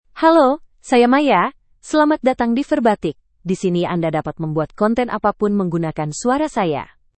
MayaFemale Indonesian AI voice
Maya is a female AI voice for Indonesian (Indonesia).
Voice: MayaGender: FemaleLanguage: Indonesian (Indonesia)ID: maya-id-id
Voice sample
Listen to Maya's female Indonesian voice.